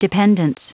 Prononciation du mot dependance en anglais (fichier audio)
Prononciation du mot : dependance